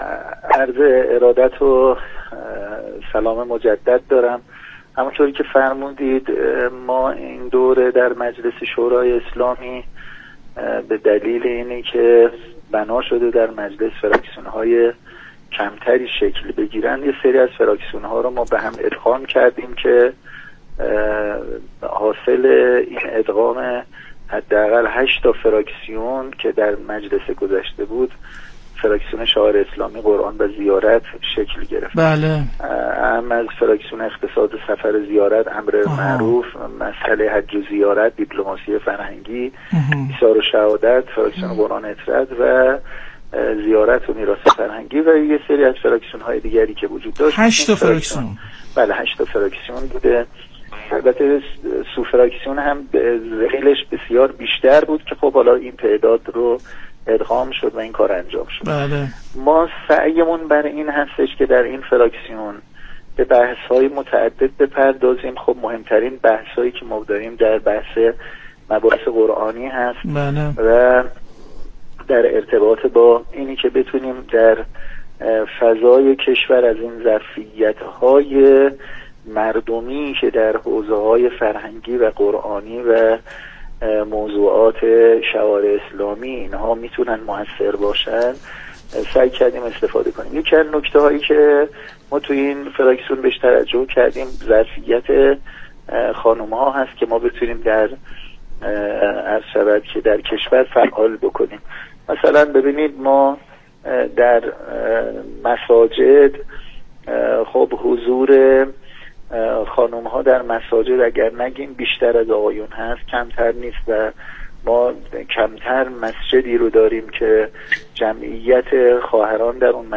تشکیل کمیته‌ای اختصاصی برای بانوان فعال قرآنی کشور در مجلس + صوتبه گزارش ایکنا به نقل از روابط عمومی شبکه رادیویی قرآن،‌ حجت‌الاسلام والمسلمین نصرالله پژمانفر؛‌ نماینده مردم مشهد و رئیس فراکسیون شعائر اسلامی، قرآن و زیارت مجلس شورای اسلامی در گفت‌وگویی تلفنی با برنامه «افق» رادیو قرآن درباره حضور کمرنگ بانوان در مجامع،‌ شوراهای بالادستی حوزه‌های دینی،‌ قرآنی و فرهنگی و ارائه راهکارهایی برای استفاده بهتر از این ظرفیت گفت: در این دوره از مجلس شورای اسلامی به دلیل اینکه بنا شد در مجلس فراکسیون‌های کمتری شکل بگیرند، یک‌سری از فراکسیون‌ها را با هم ادغام کردیم که حاصل ادغام حداقل هشت فراکسیون، «فراکسیون شعائر اسلامی، قرآن و زیارت» شکل گرفت.